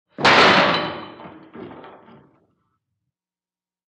PrisonCellDoorSlam PE802004
DOORS VARIOUS PRISON DOORS: Cell door slam, fast.